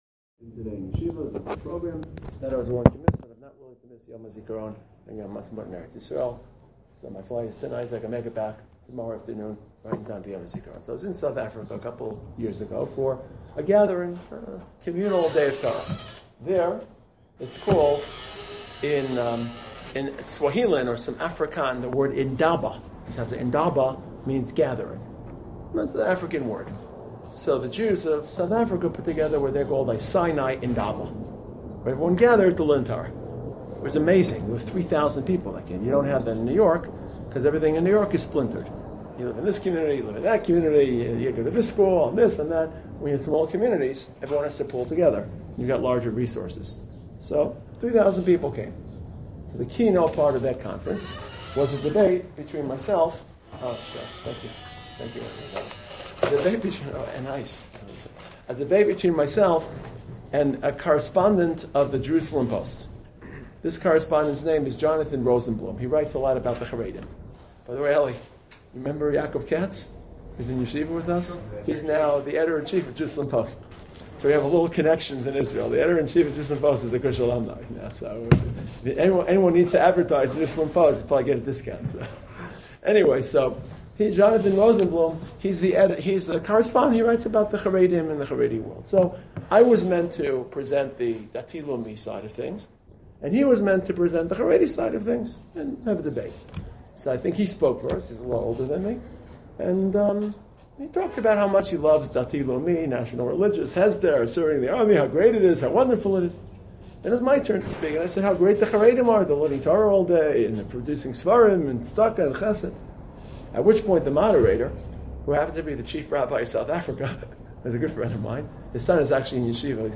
Given to American High School students.